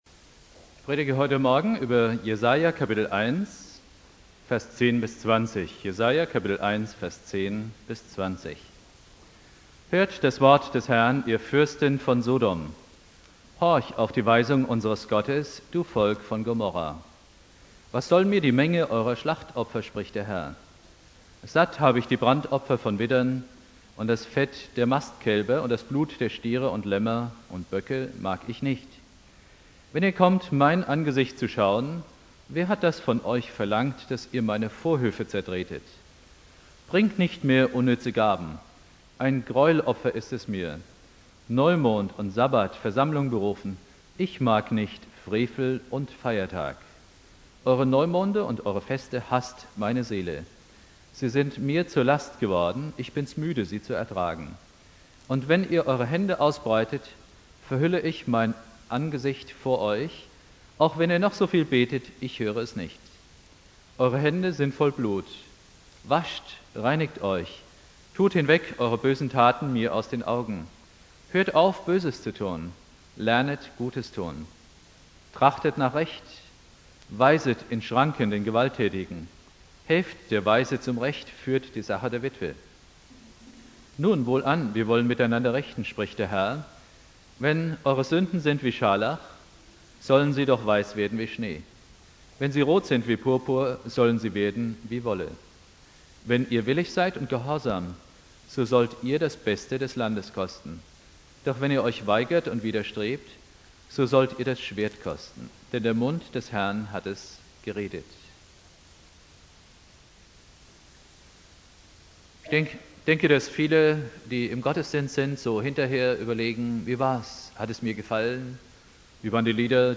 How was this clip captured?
Buß- und Bettag Prediger